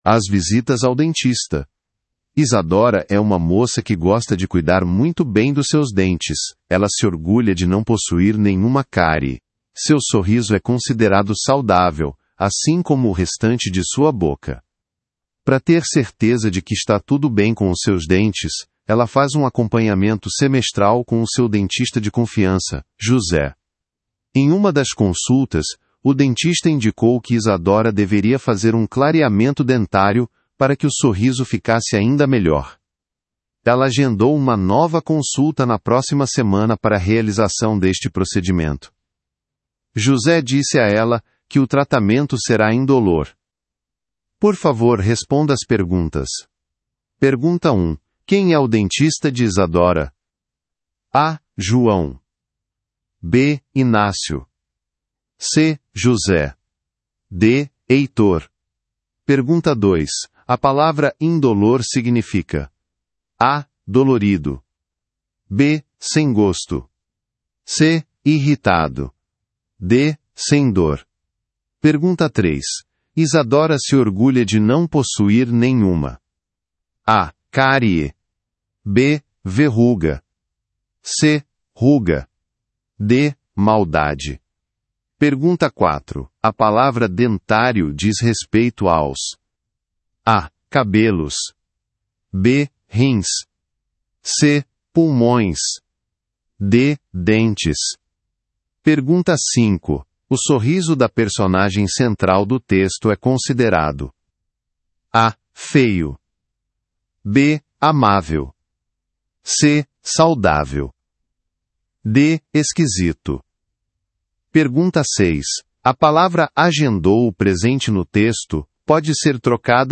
Brasile